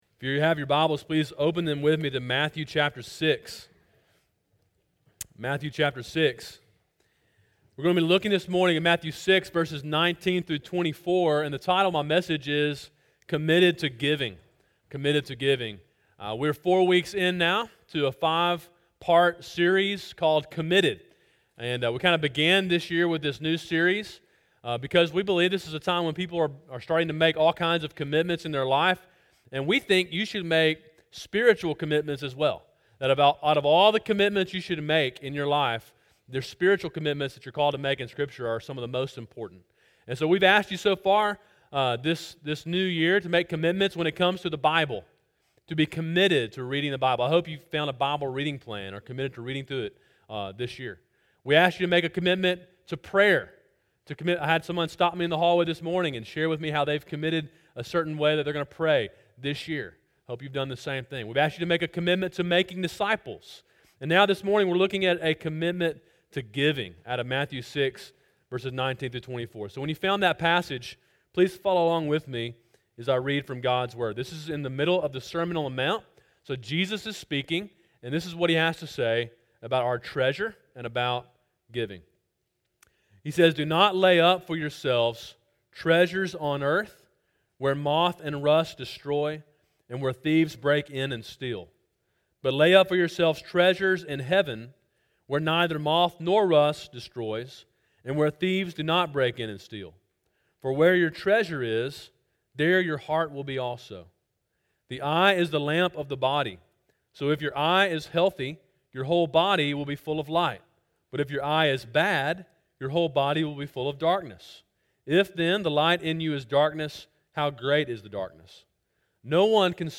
Sermon: “Committed to Giving” (Matthew 6:19-24) – Calvary Baptist Church
sermon2-04-18.mp3